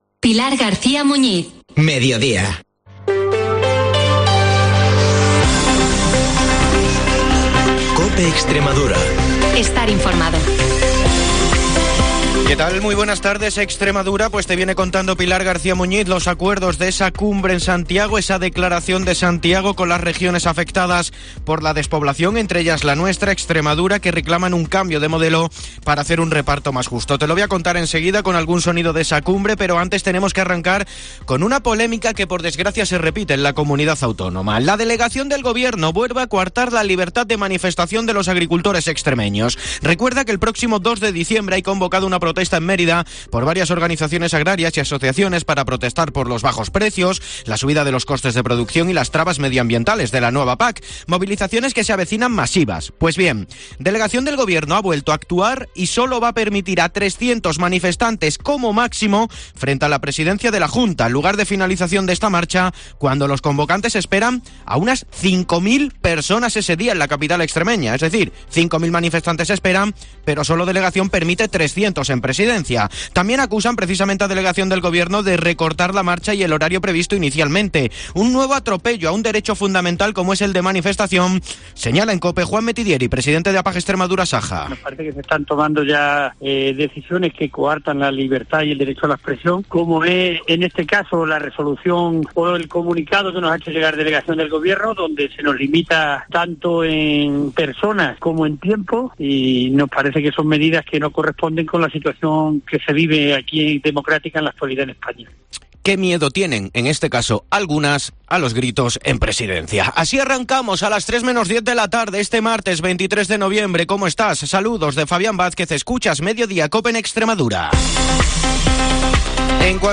Boletines COPE